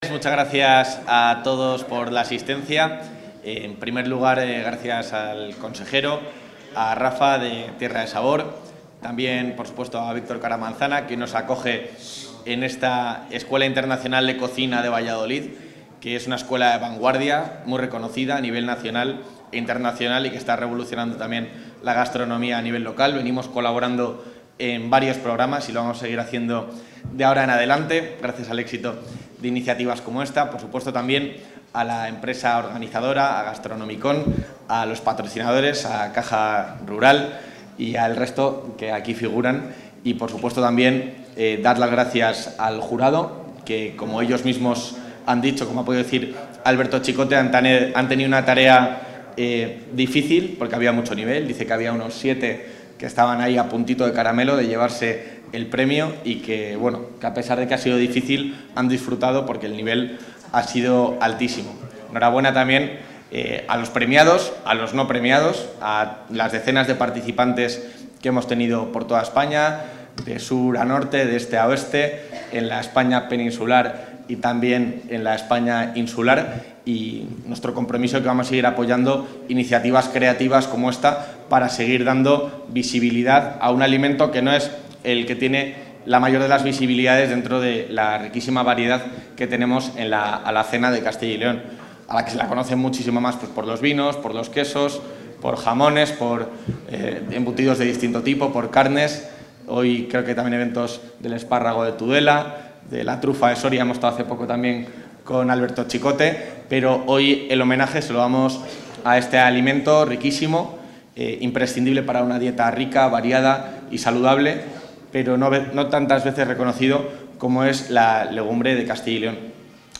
Intervención del vicepresidente de la Junta.